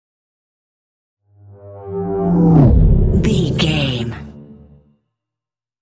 Whoosh deep large
Sound Effects
dark
futuristic
tension